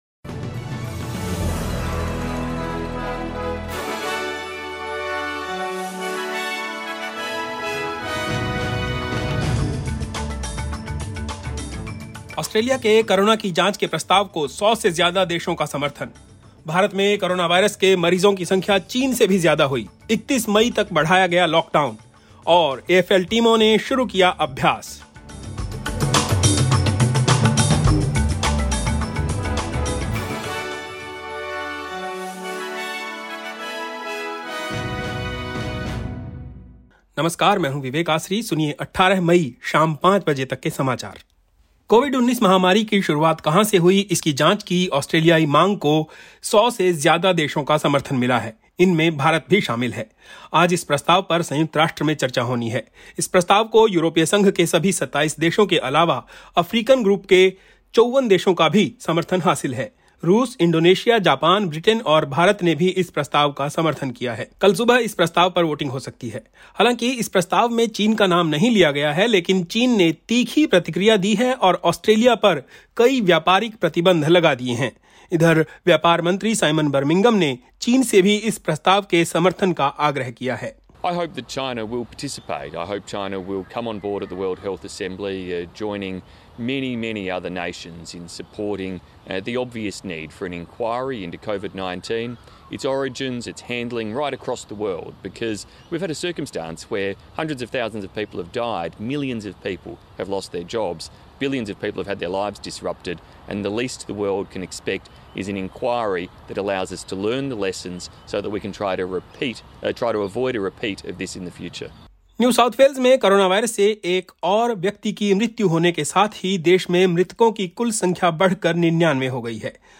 Hindi News 18 May 2020